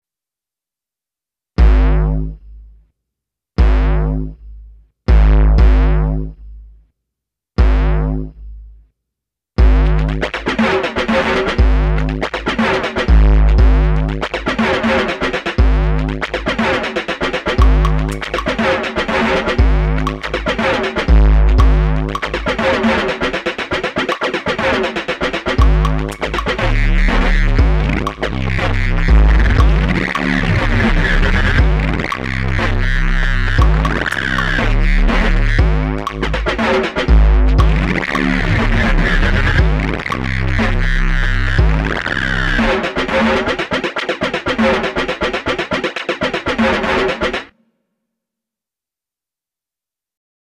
I miss my MnM sounds, so I’m pushing into the territory with a QY100.
As much as I love all things Monomachine it’s nice to hear something different.